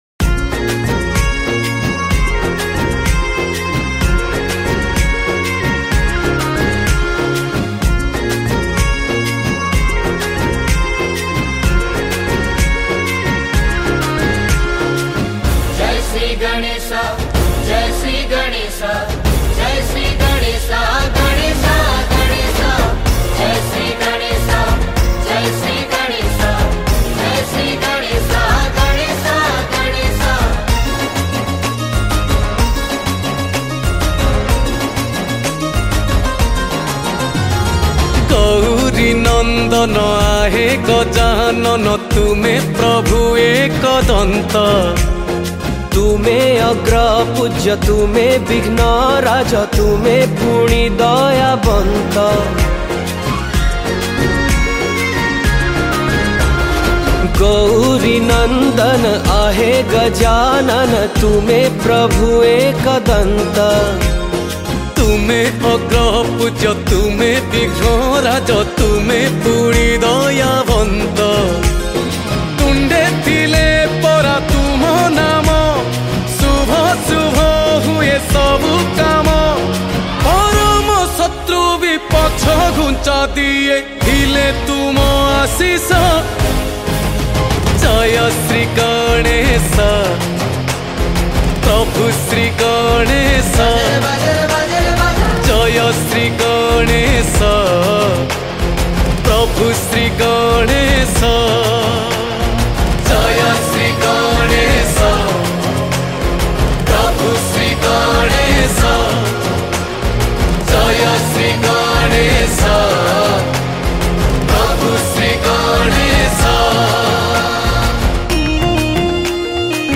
Ganesh Puja Special Mp3 Song Songs Download
Keyboard
Chorus